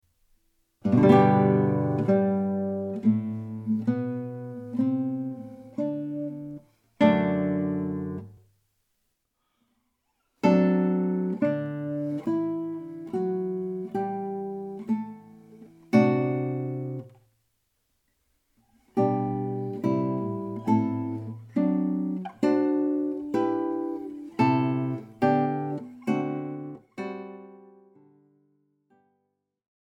Gitarrenmusik aus Wien von 1800-1856
Besetzung: Gitarre